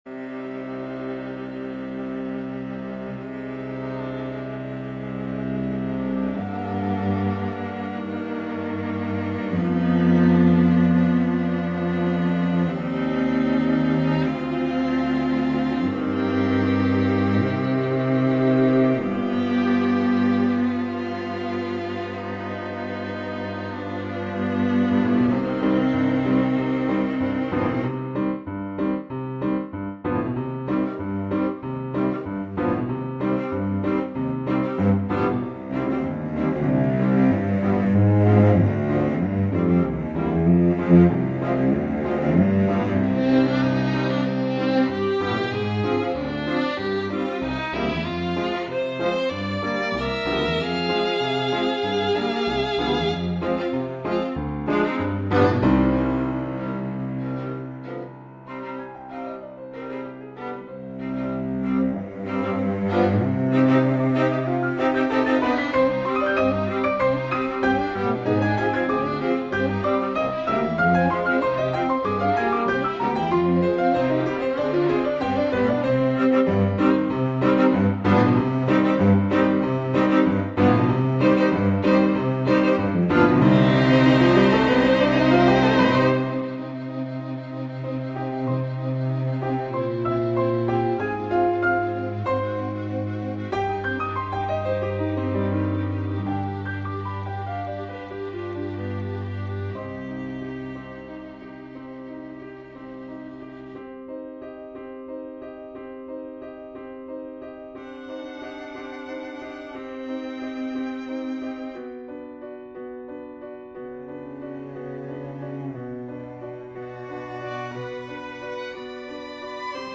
2025 Halloween Competition - DANCE FROM THE SKELETON BALL (Submission) - Chamber Music - Young Composers Music Forum